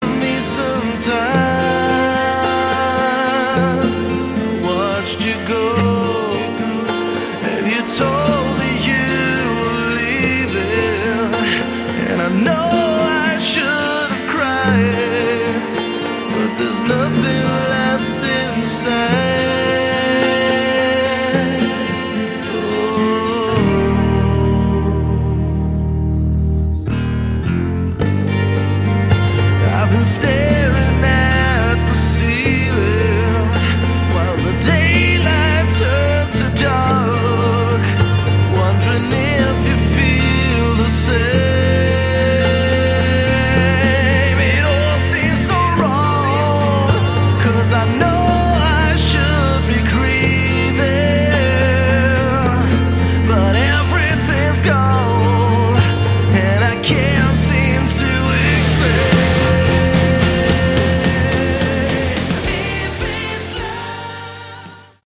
Category: Hard Rock
vocals
guitar
drums
bass